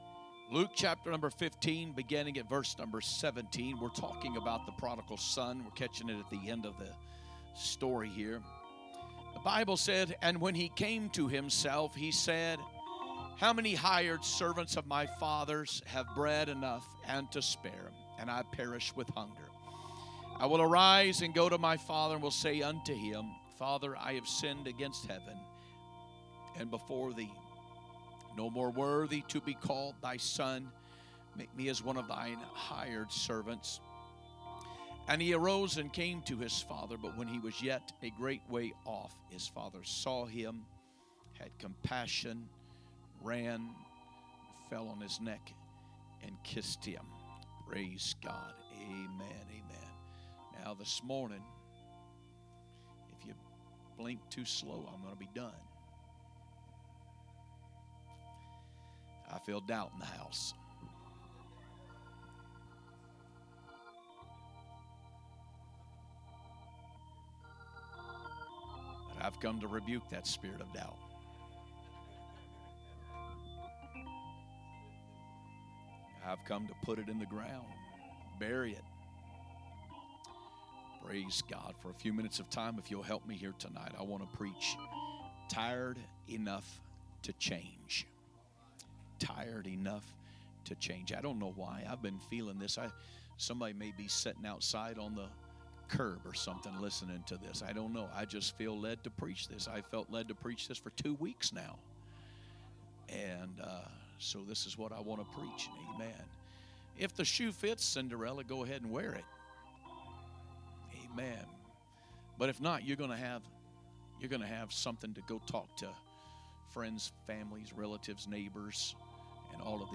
11/23/2025 Sunday Morning Service